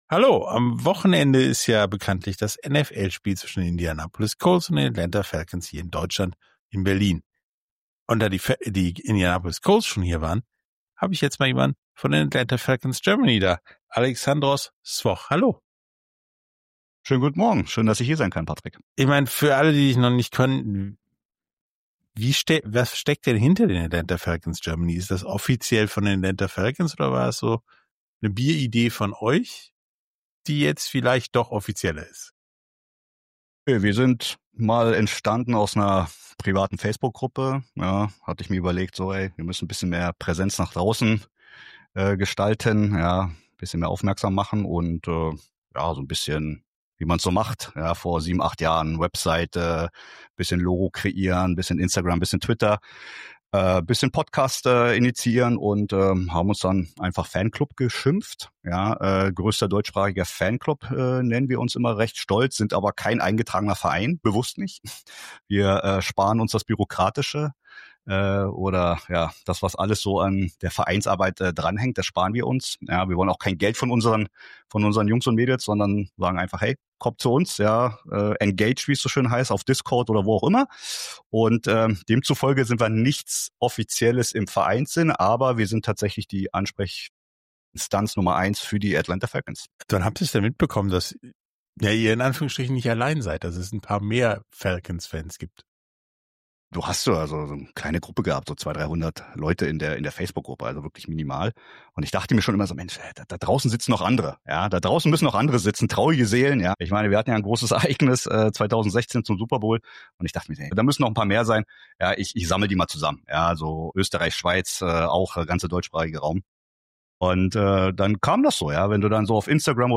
Beschreibung vor 1 Monat Rise Up Germany – Die Atlanta Falcons kommen nach Berlin! In diesem besonderen Interview dreht sich alles um das NFL-Spiel in Berlin und die Atlanta Falcons Germany, den offiziellen deutschen Fanklub des Teams aus Georgia.